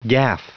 Prononciation du mot gaffe en anglais (fichier audio)